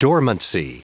Prononciation du mot dormancy en anglais (fichier audio)
Prononciation du mot : dormancy